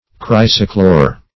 Search Result for " chrysochlore" : The Collaborative International Dictionary of English v.0.48: Chrysochlore \Chrys"o*chlore\, n. [Gr. chryso`s gold + chlwro`s light green: cf. F. chrysochlore.]